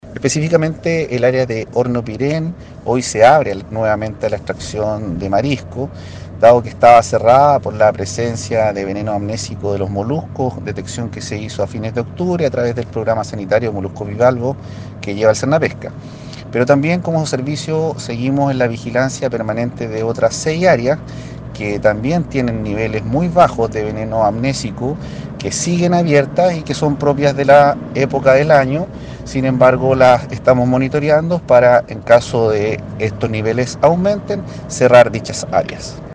Los niveles analizados son bajos, pero el monitoreo en diferentes áreas de la Región continúan realizándose para lograr establecer una detección oportuna, indicó Branny Montecinos, director (S) de Sernapesca.